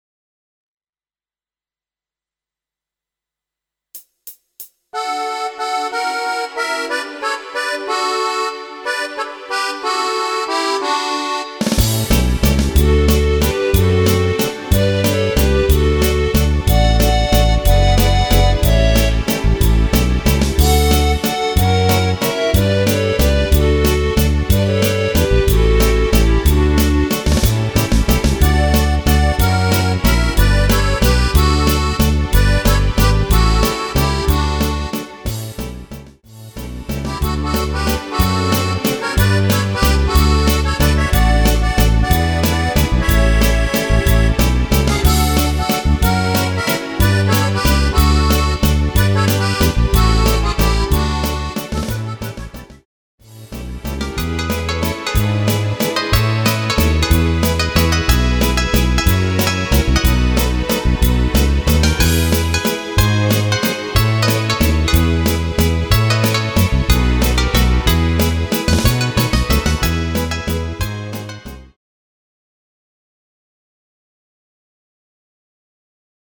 Rubrika: Pop, rock, beat
- valčík